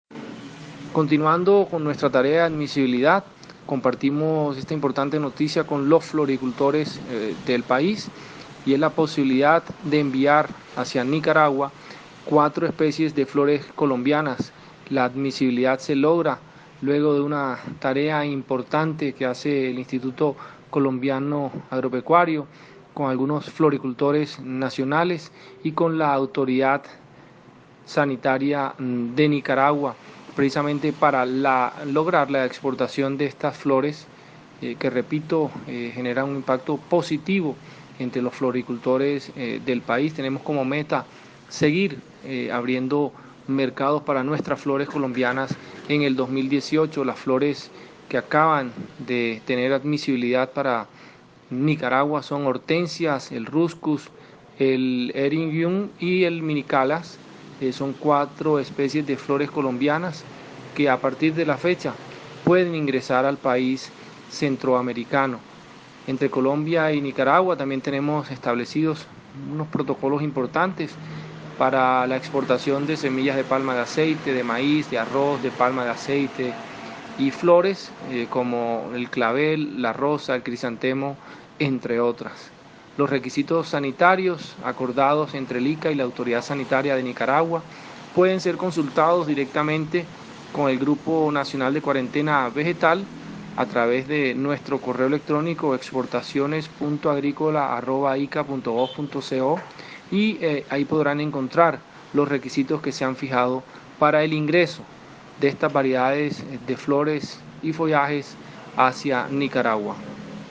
• Declaraciones del gerente general del ICA:
Audio_Luis-Humberto-Martinez_gerente-ICA.wma